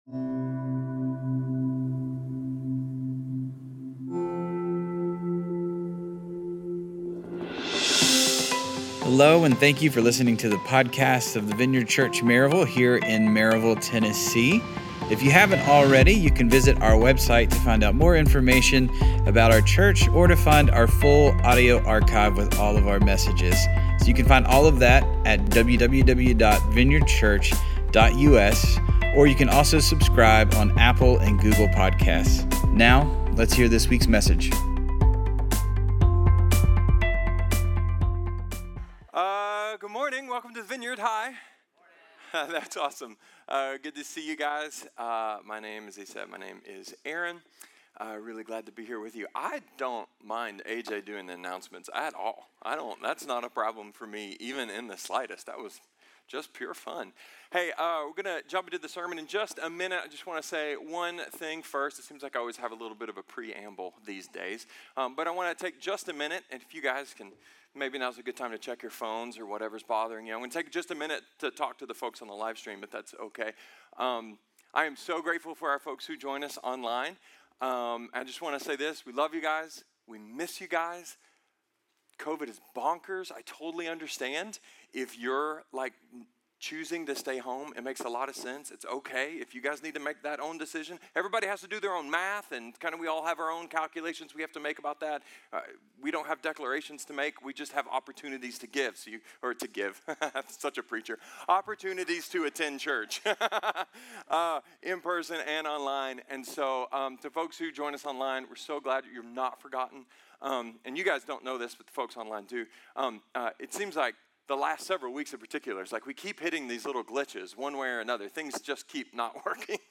A sermon about falling in and out of love.